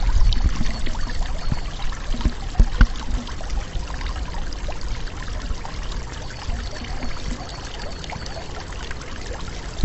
Hemos cogido el sonido del agua de una fuente（由IES Baldiri（El Prat de Llobregat 巴塞罗那）的学生在城市不同城市空间的现场录音研讨会期间录制。录音设备：ZOOM H1）。